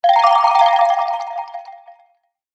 Text-drop-sound-effect.mp3